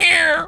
bird_tweety_hurt_04.wav